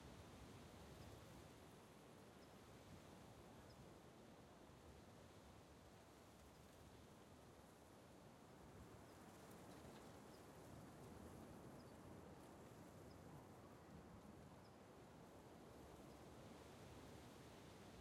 sfx-jfe-amb-loop-3.ogg